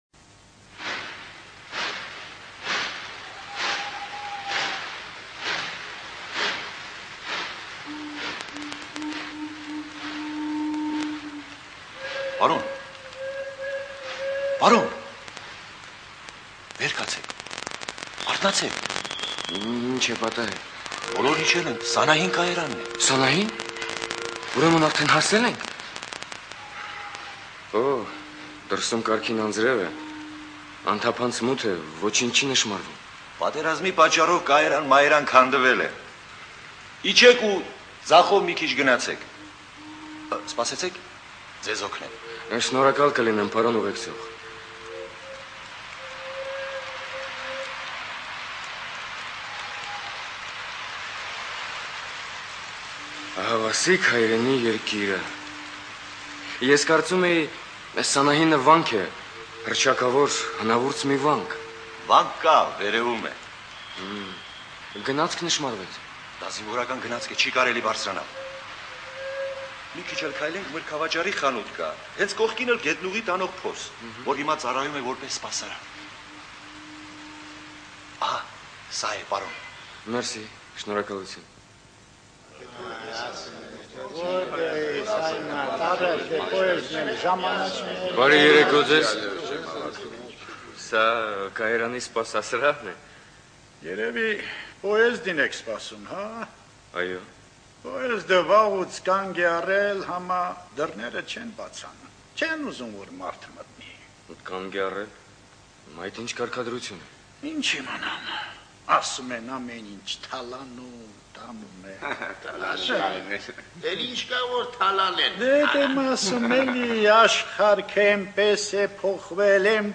ЖанрРадиоспектакли на армянском языке